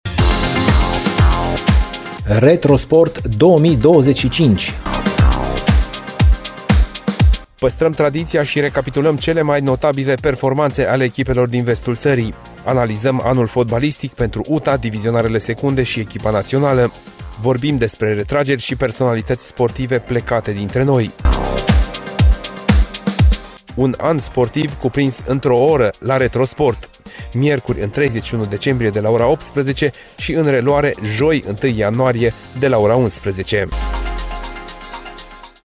Promo-Retro-Sport-2025.mp3